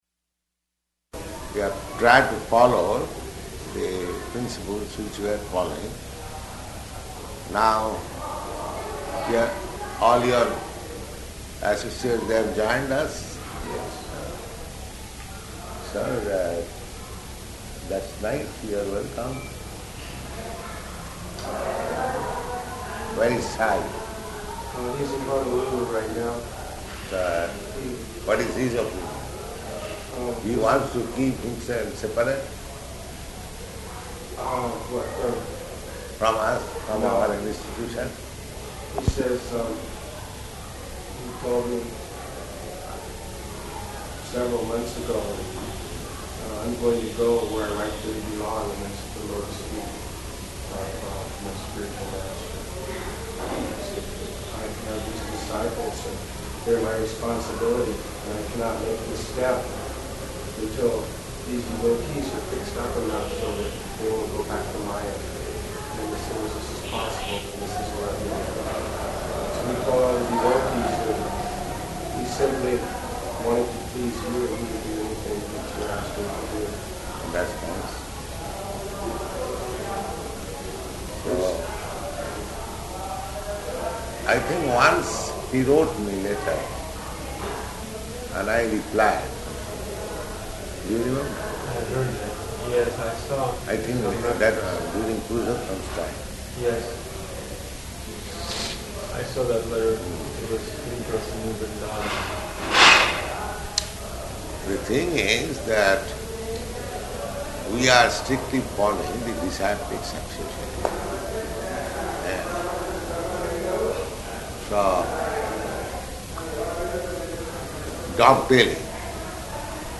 Room Conversation about Siddhasvarūpa --:-- --:-- Type: Conversation Dated: March 21st 1971 Location: Bombay Audio file: 710321R1-BOMBAY.mp3 Prabhupāda: You have tried to follow the principles which we are following.